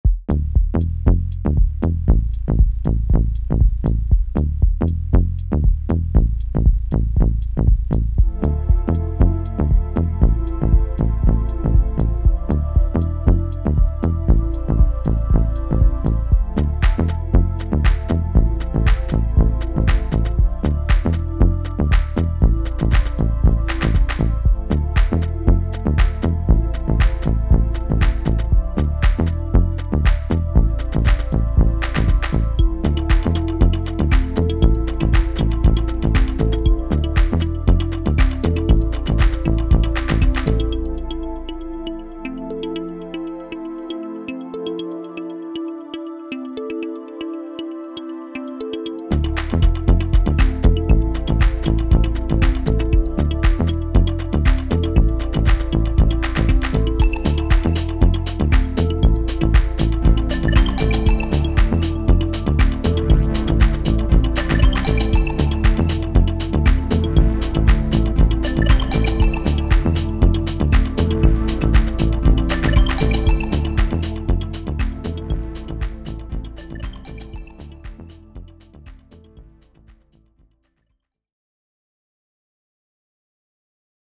• The drums are from sample packs, the bass is in the drum kit using 16 pitches.
• The pad is the preset Hallowed Pad, using the keys mode that doesn’t obscure all the notes.
• The kalimba style lead is the preset Condo.
• The other 2 sounds are a sonar blip type sound and a chord from a sample pack, also using a drum kit.
• So 2 drum kits playing percussive and melodic stuff, 2 Move presets.
Muscially nothing super exciting lots of stuff just repeats.
This recording sounds so clean, pristine and well-engineered.